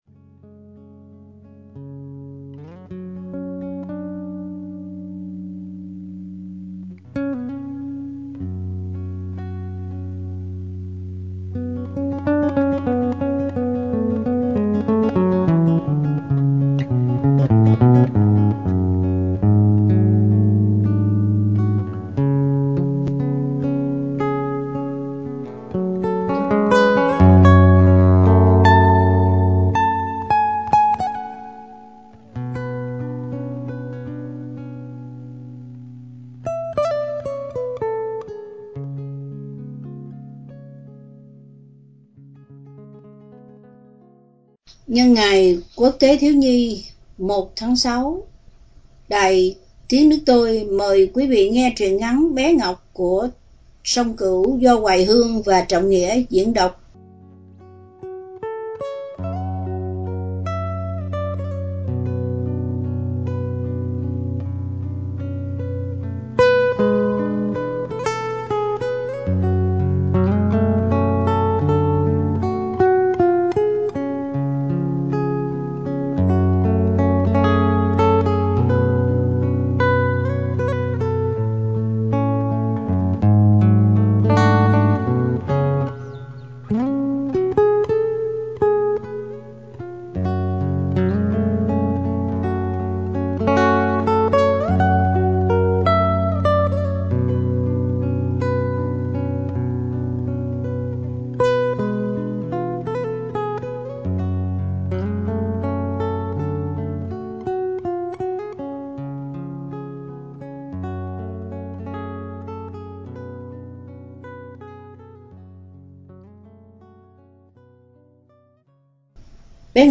Đọc Truyện Chọn Lọc – Truyện Ngắn – Bé Ngọc – Tác Giả Sông Cửu – Radio Tiếng Nước Tôi San Diego